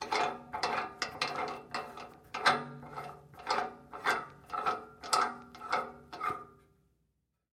Крутим штурвал водопроводного вентиля